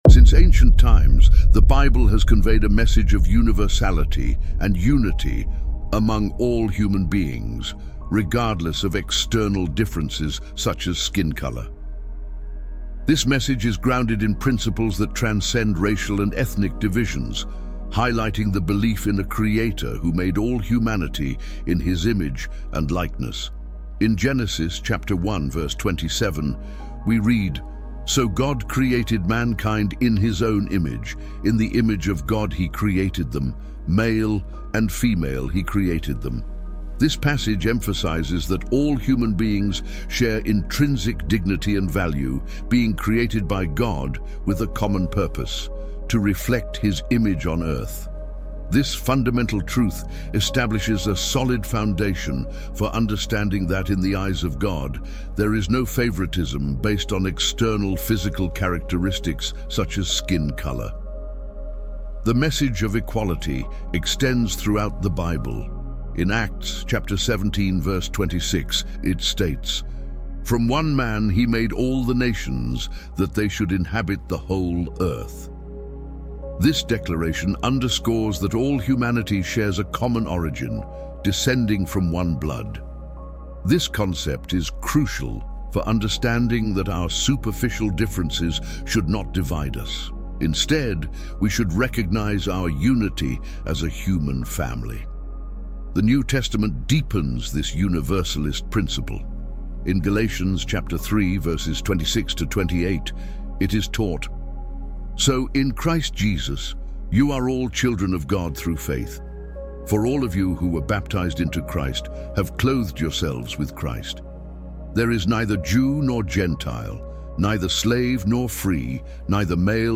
Before every episode of The Bible Stories: Words of Life, we want to share something many listeners quietly appreciate the moment they press play after a long day. All advertisements are placed at the very beginning of the episode so nothing interrupts the experience once the story begins.